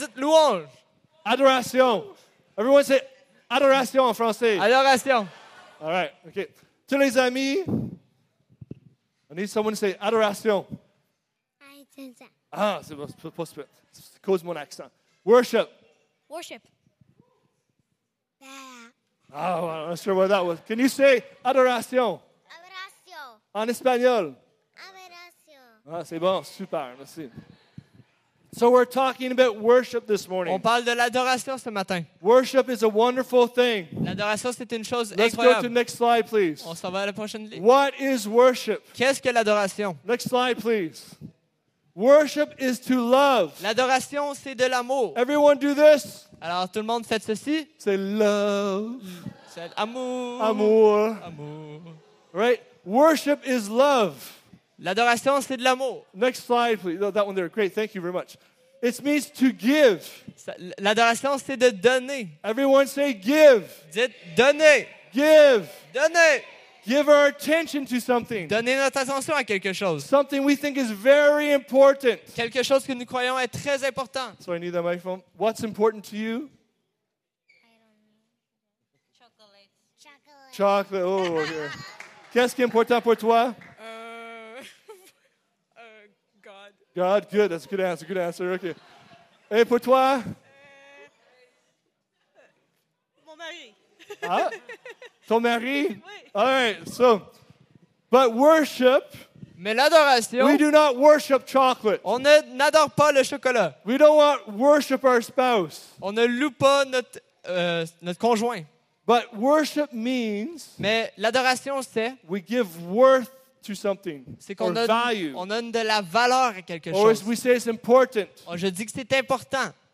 Sermons | Evangel Pentecostal Church